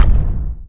step3.wav